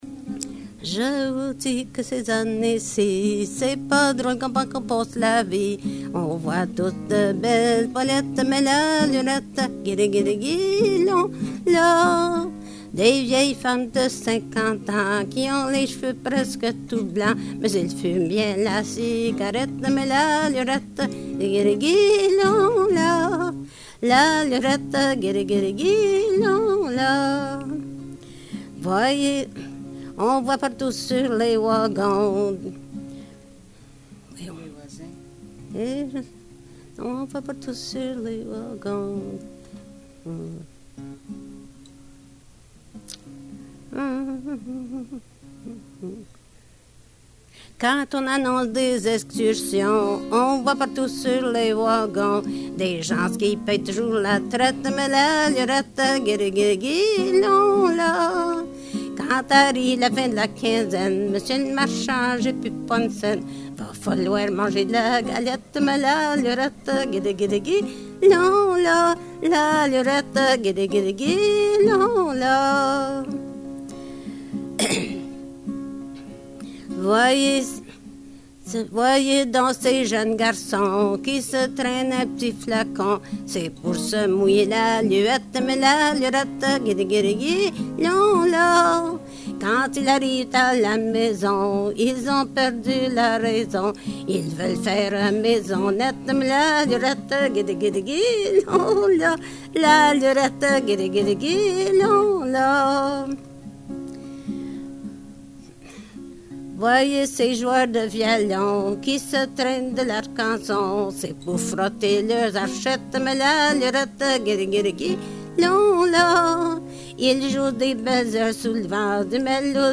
Folk Songs, French--New England